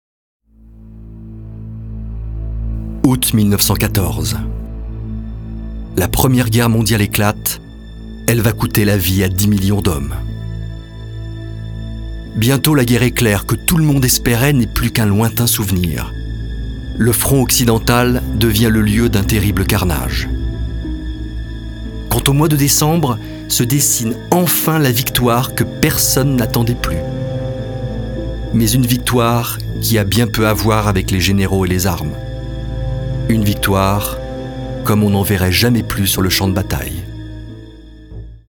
Voix off
Docu Historique
Narrateur